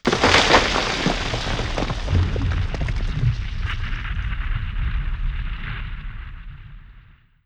snd_jack_debris2.wav